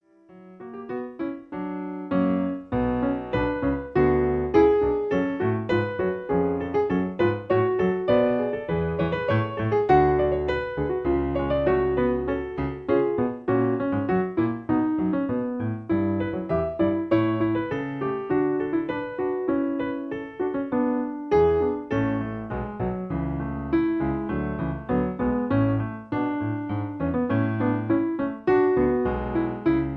In B. Piano Accompaniment